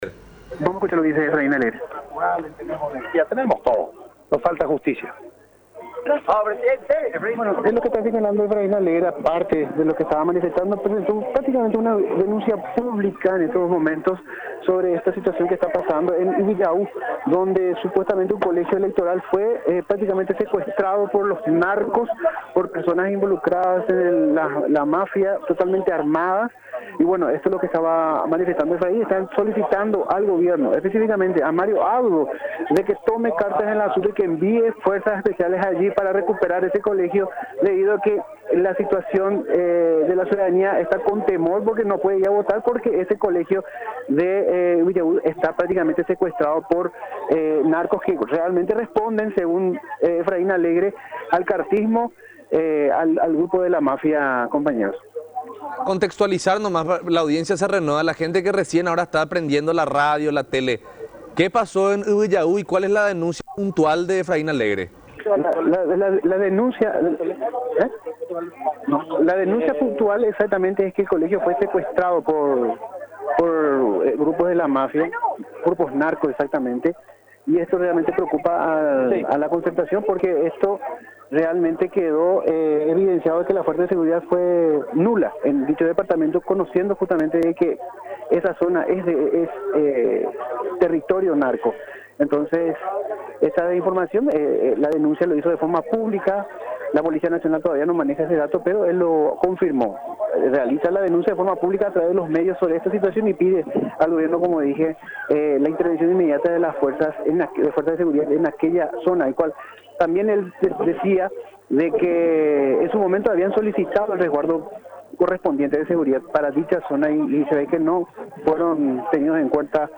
“Los narcos tomaron el colegio electoral en Yby Pytâ armados. Le responsabilizamos al presidente de la República Mario Abdo Benítez, a quien en su momento en tiempo adecuado precisamente refuerzo para Yby Pytâ, que sabemos que es zona de narcos, narcos que están vinculados al proyecto del continuismo”, denunció Alegre ante los medios de prensa este domingo.